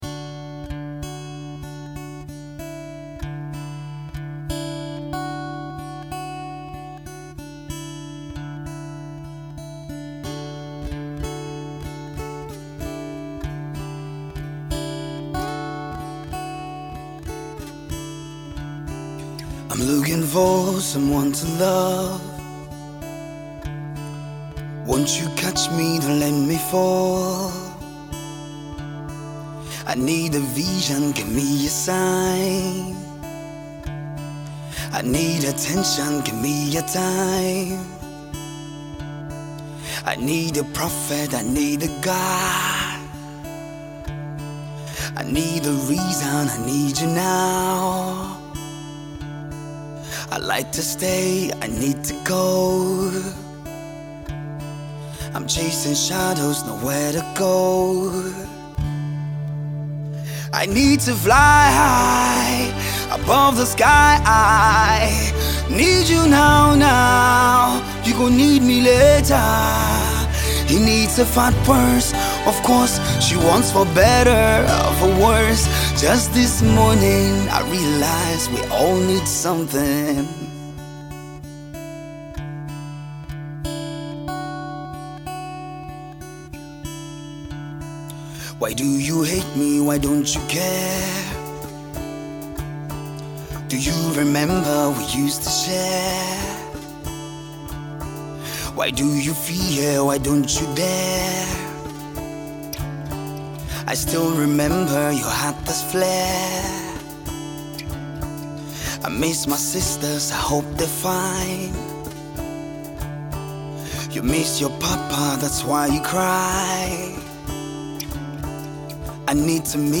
inspiring and truly expressive acoustic rendition